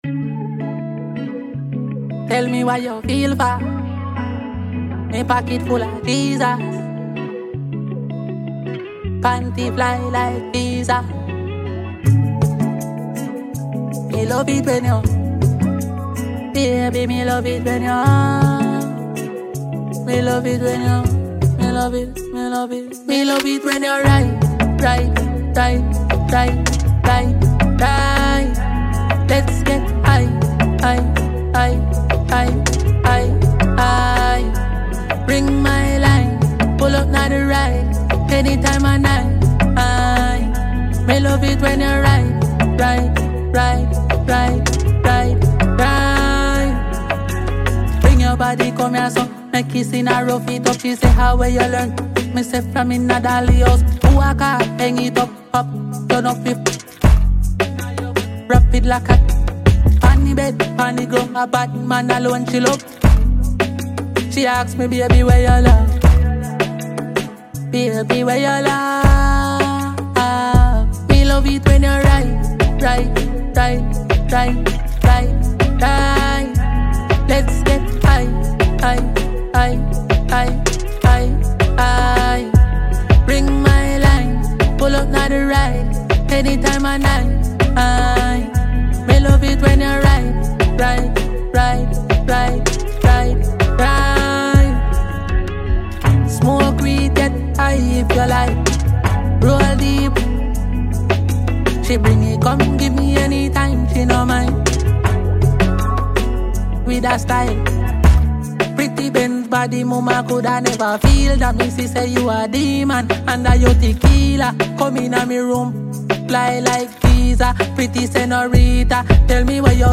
Reggae
Jamaican trap-dancehall artist